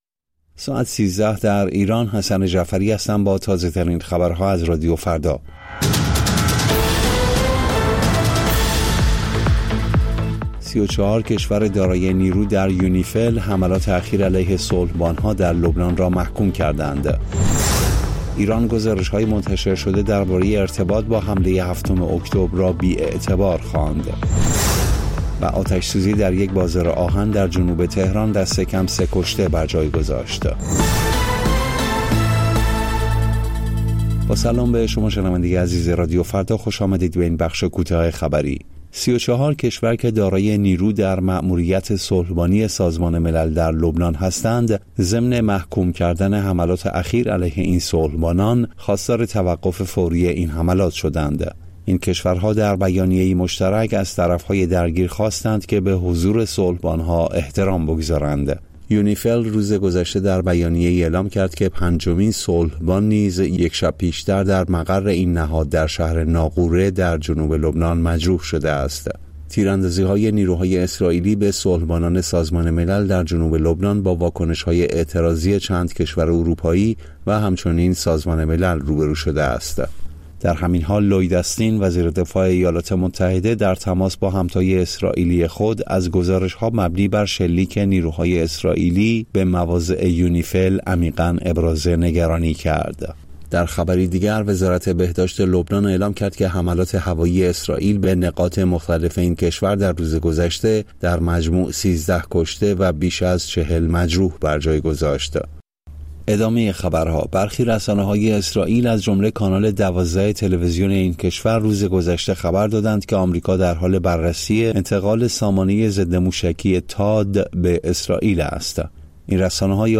سرخط خبرها ۱۳:۰۰